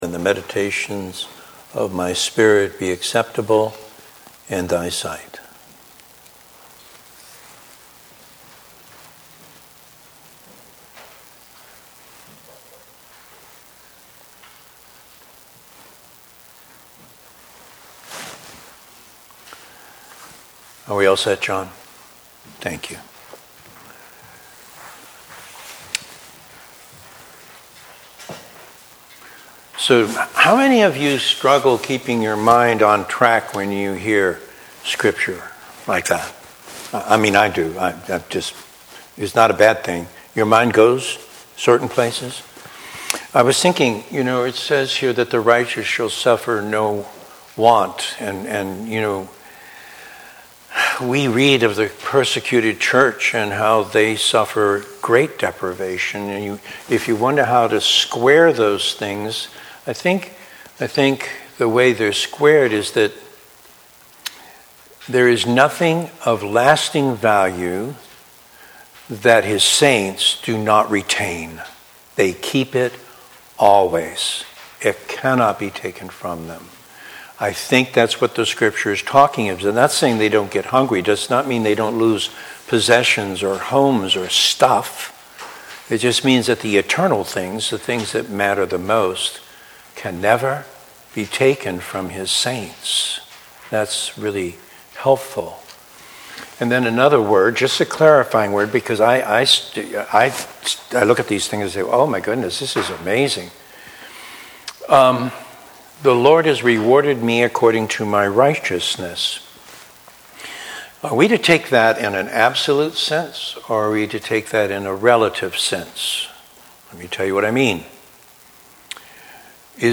Sermons | East Winthrop Baptist Church | Page 10